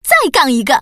Index of /fujian_ceshi_ad/update/1208/res/sfx/common_woman/